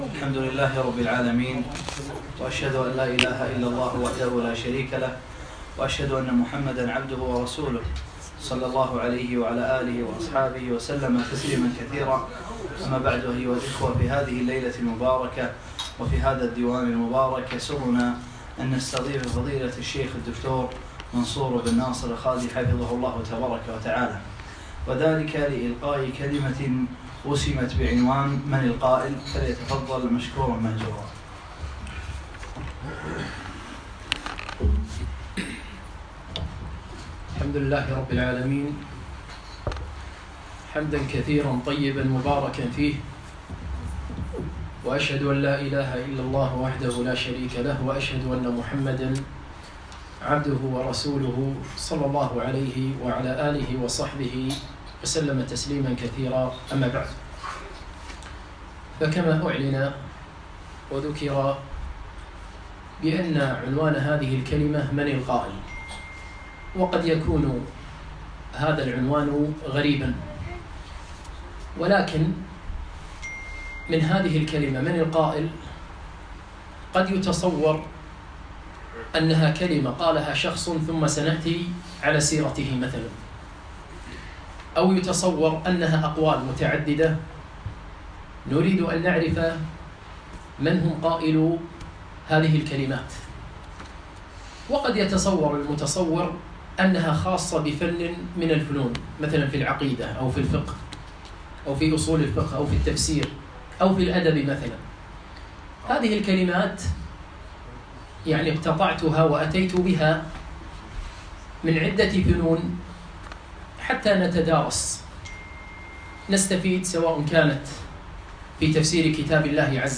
1- من القائل ؟ - المحاضرة الأولى